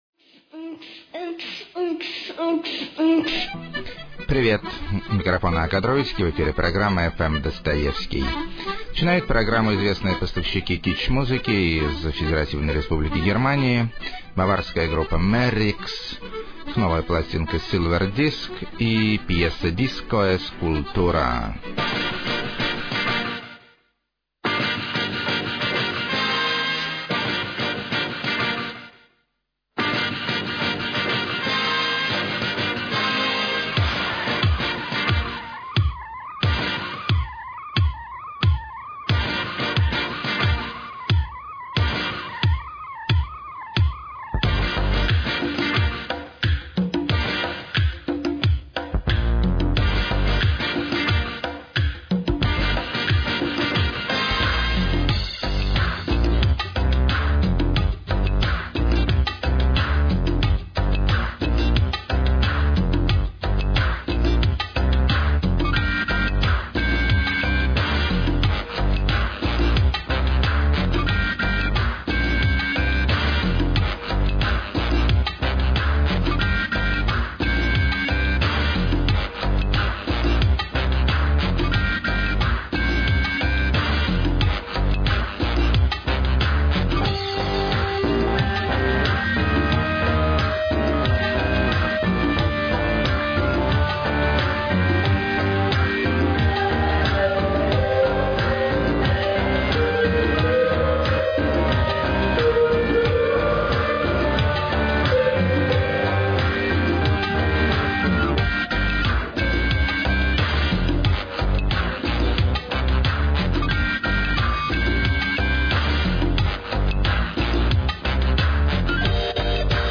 Авант-попсовый Духовой Оркестр.] 3.
Славянский Фолк-транс.
Пост-грандж, Отличные Риффы.
Замороченный Lo-fi/slow-core И Что-то Там Еще.
Попсовый Джазовый Свинг.